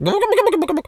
pgs/Assets/Audio/Animal_Impersonations/turkey_ostrich_gobble_07.wav at master
turkey_ostrich_gobble_07.wav